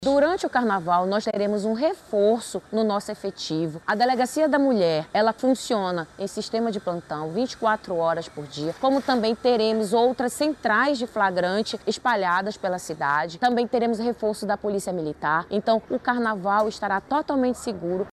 SONORA-2-ORIENTACOES-MULHERES-CARNAVAL-.mp3